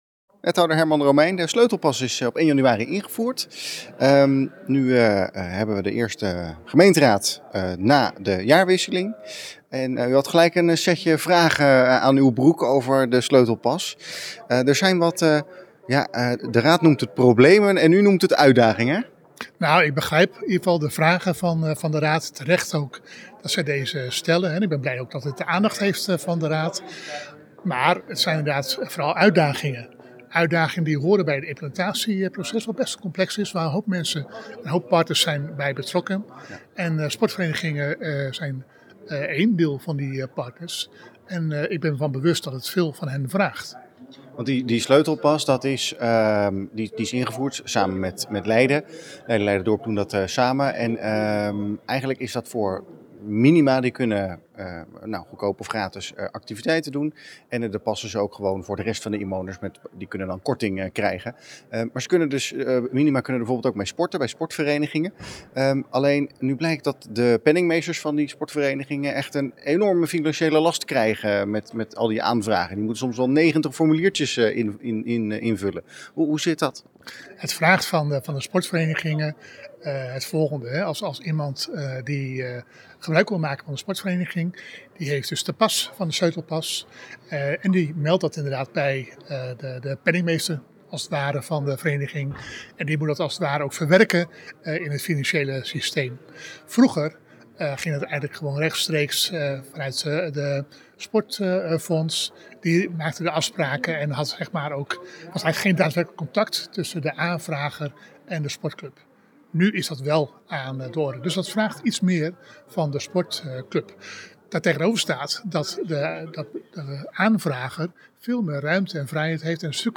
Wethouder Herman Romeijn na afloop van de vergadering:
Wethouder-Herman-Romeijn-over-Sleutelpas.mp3